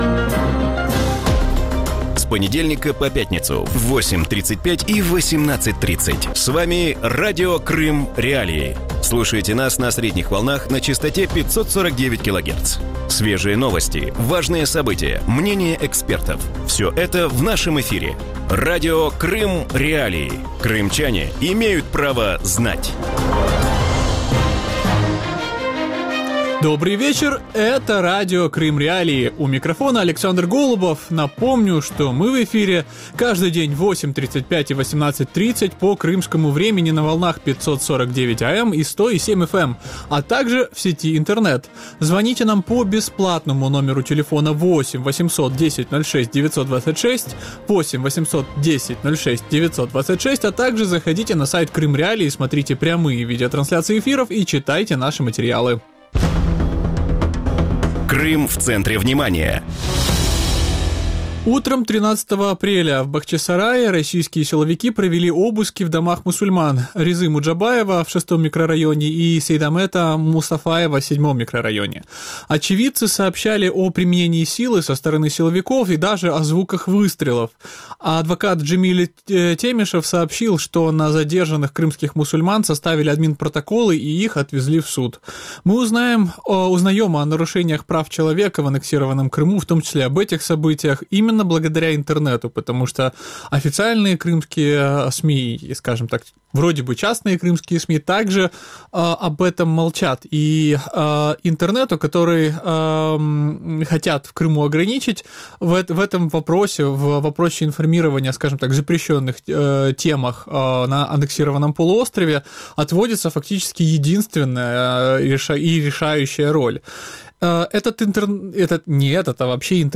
В вечернем эфире Радио Крым.Реалии обсуждают инициативу российских властей Крыма фильтровать интернет-трафик. Возможно ли создать систему для фильтрации интернет-трафика, как этого хотят в России и в аннексированном Крыму?